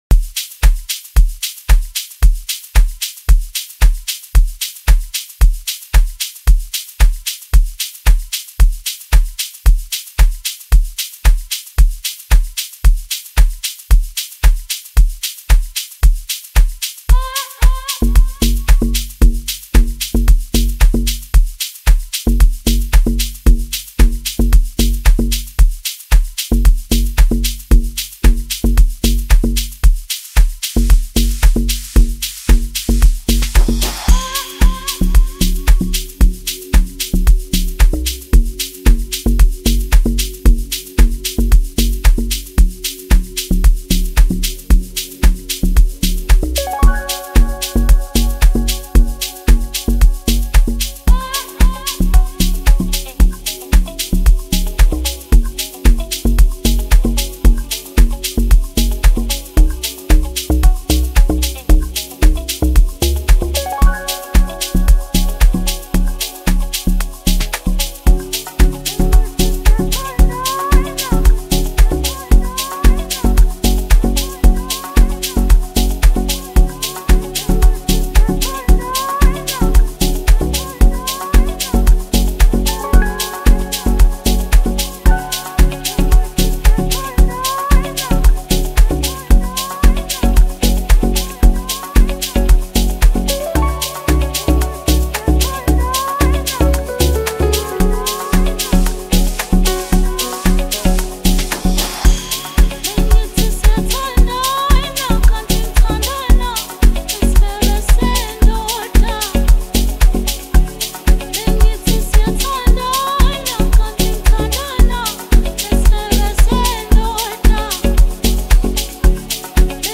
emphasizing rich vocals